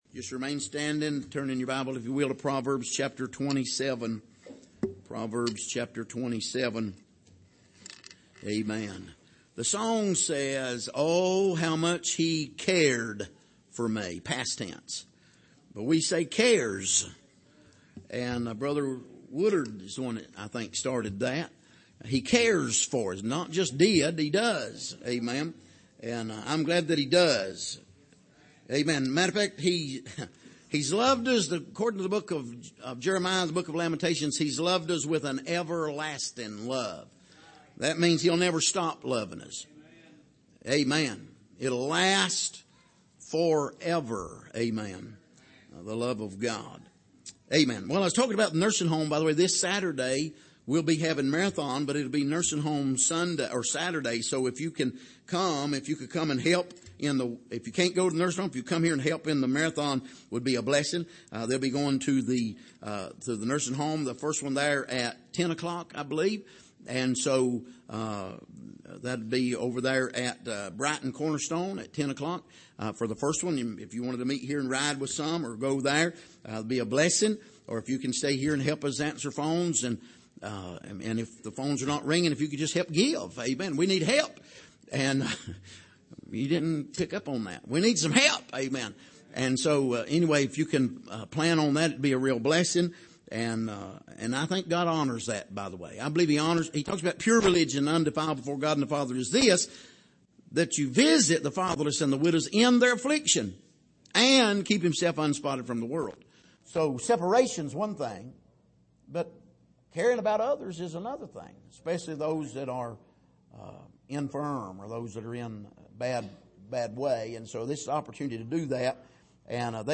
Passage: Proverbs 27:19-27 Service: Sunday Evening